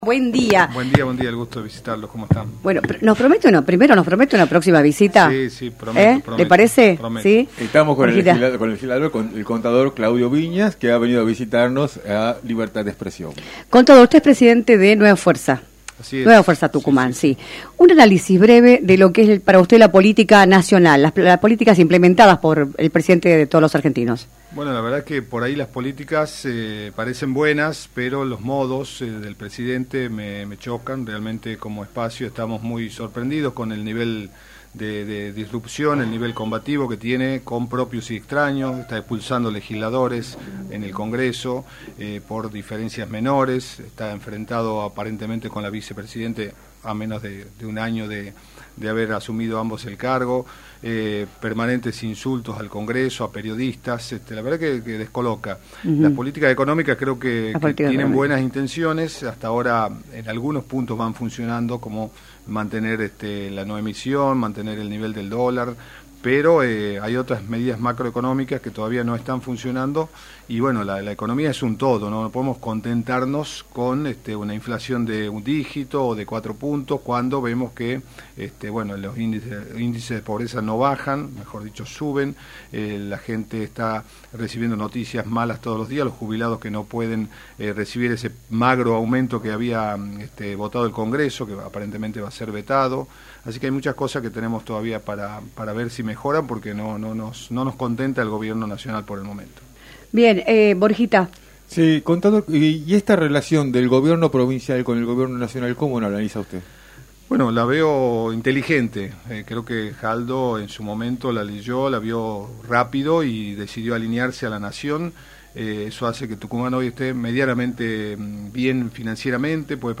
“Las políticas implementadas por el Gobierno Nacional parecen buenas pero los modos del Presidente me chocan mucho, me sorprende el nivel disruptivo y confrontativo que tiene con propios y extraños, echando a legisladores por discusiones menores y con insultos constantes a los periodistas, por lo que si bien hay políticas económicas que si funcionan, hay otras que no, como con el tema de los jubilados” señaló Viña en su visita a  “Libertad de Expresión”, por la 106.9.